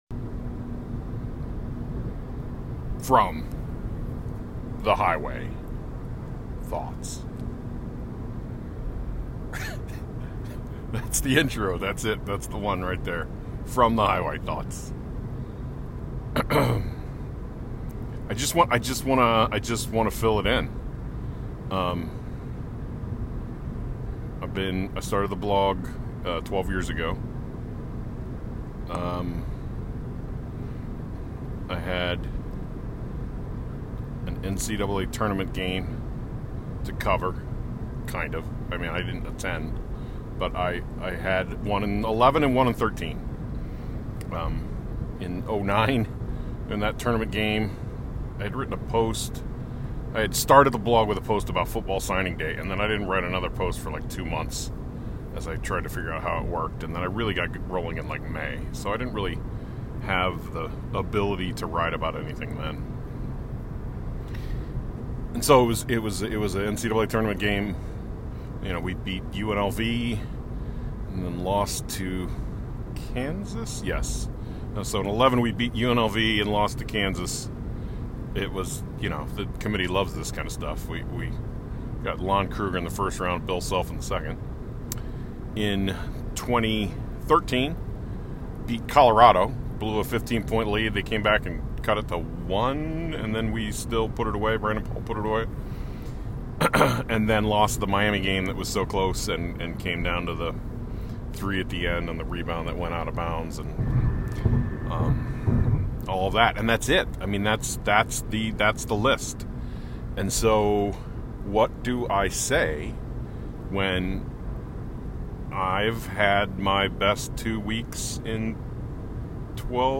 while driving back from Indianapolis after the Big Ten Tournament Championship Game.